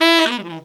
Index of /90_sSampleCDs/Best Service ProSamples vol.25 - Pop & Funk Brass [AKAI] 1CD/Partition C/TENOR FX2